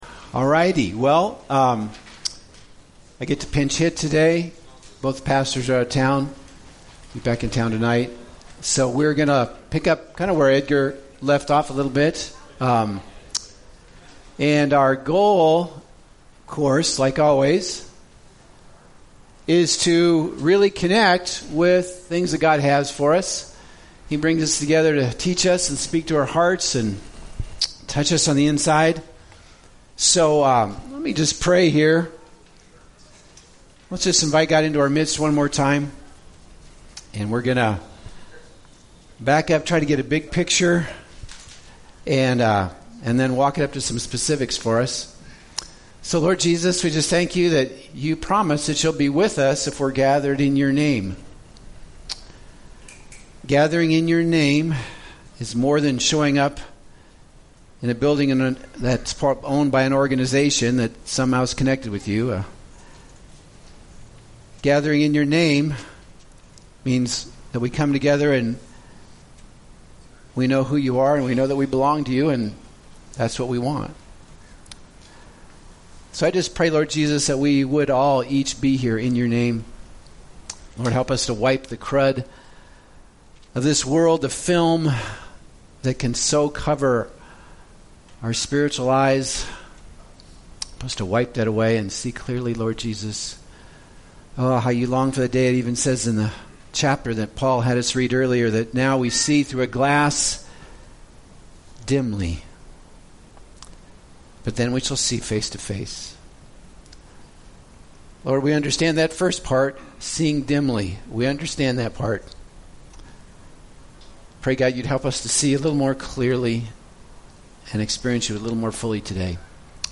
Galatians Service Type: Sunday Bible Text